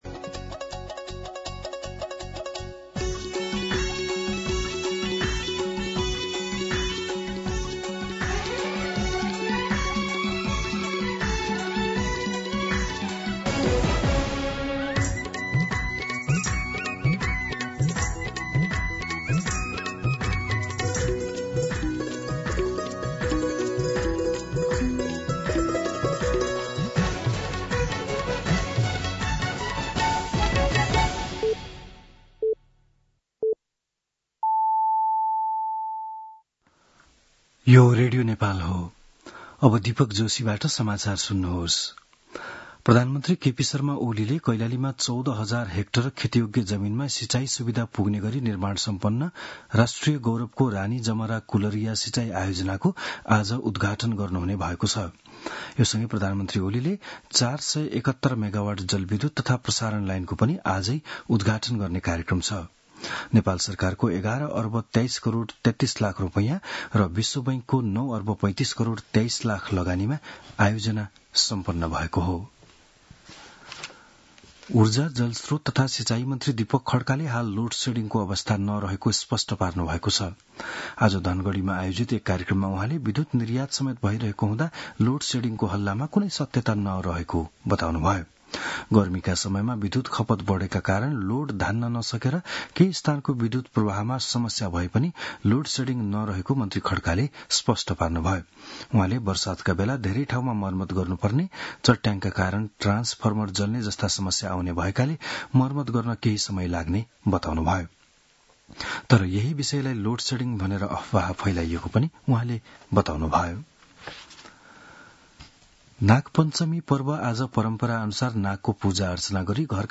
बिहान ११ बजेको नेपाली समाचार : १३ साउन , २०८२